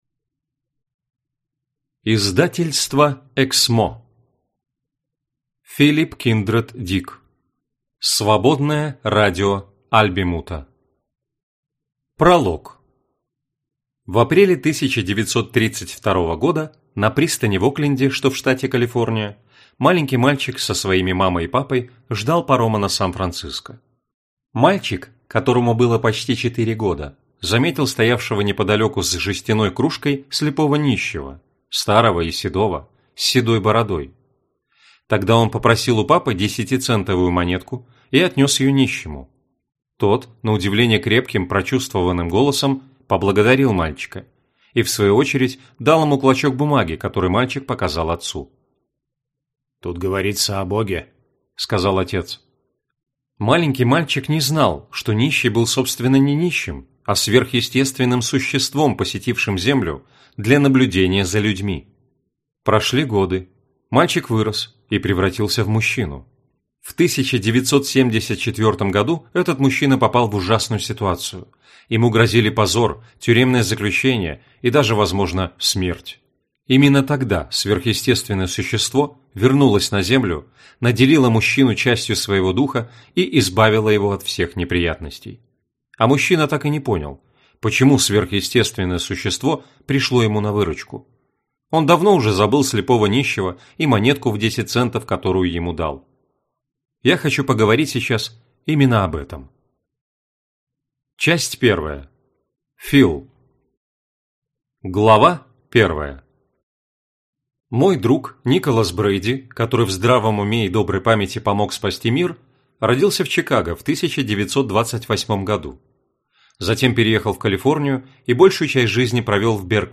Аудиокнига Свободное радио Альбемута | Библиотека аудиокниг